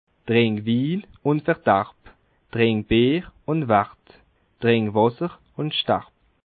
Expressions populaires
Bas Rhin
Ville Prononciation 67
Schiltigheim